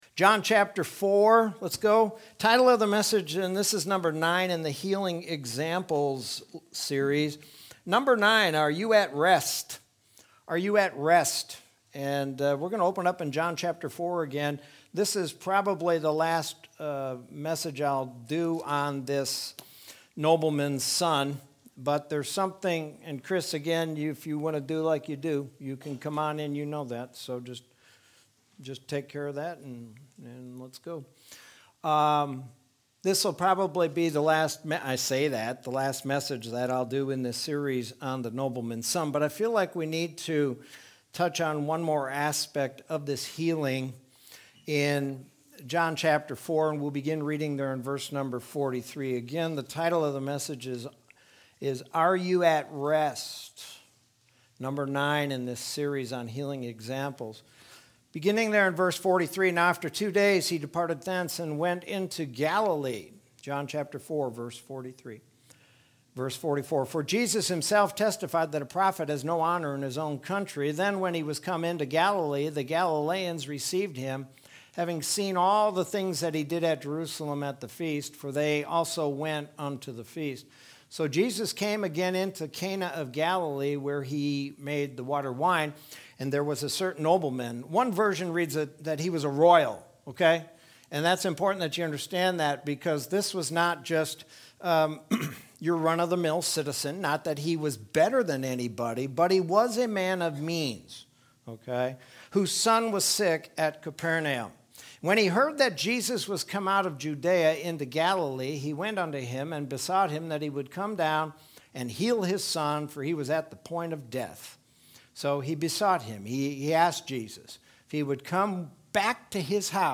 Sermon from Wednesday, March 17th, 2021.